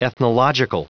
Prononciation du mot ethnological en anglais (fichier audio)
Prononciation du mot : ethnological